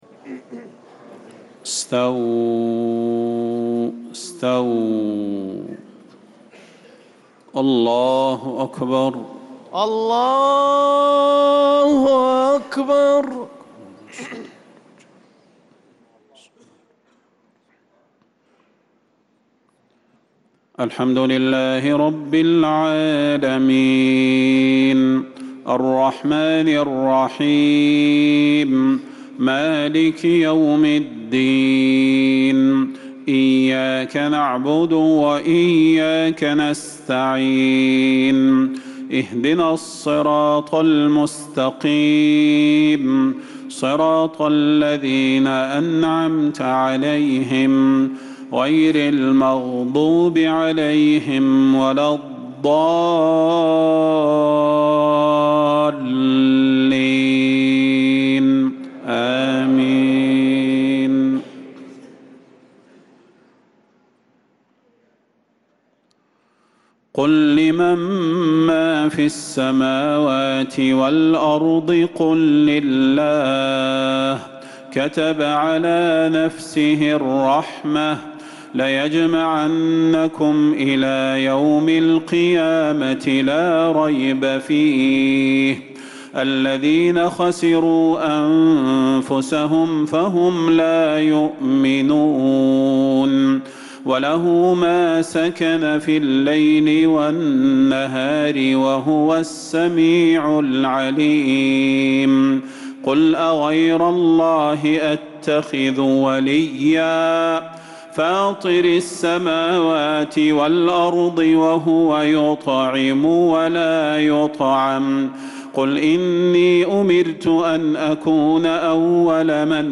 عشاء الاثنين 12 محرم 1447هـ من سورة الأنعام 12-19 | Isha prayer from Surah Al-An’aam 7-7-2025 > 1447 🕌 > الفروض - تلاوات الحرمين